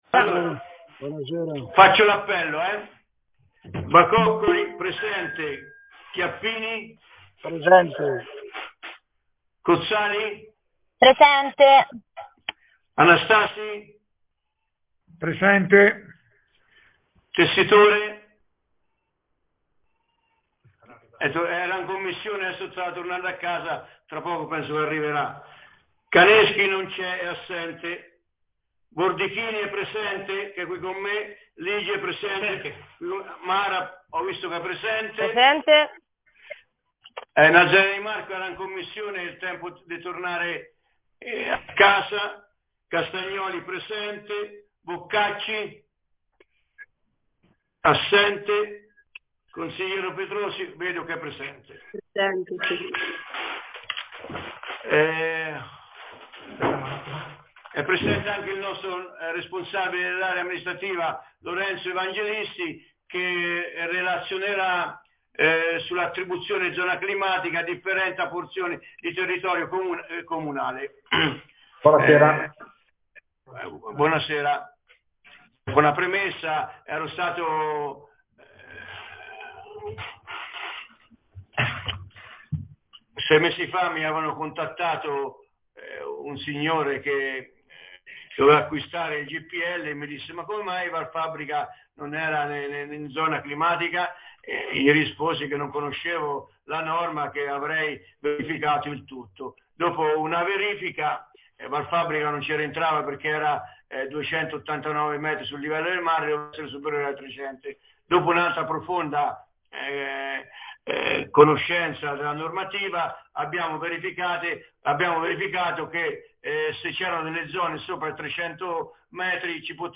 Consiglio Comunale del 16 Gennaio 2023